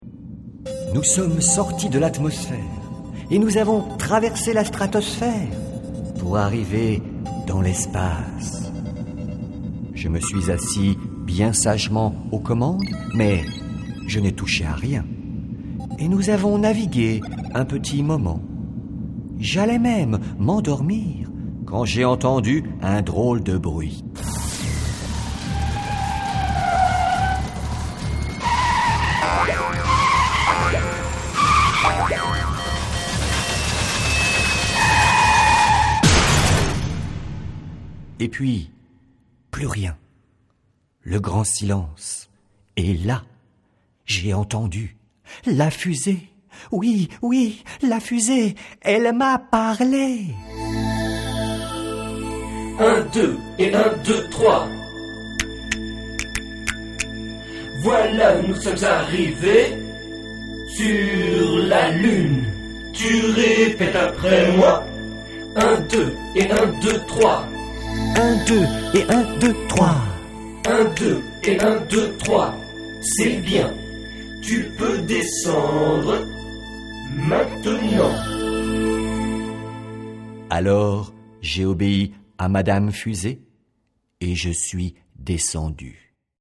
Lecture de Voyage dans l'espace.
Lecture du spectacle enfant Swing la Lune.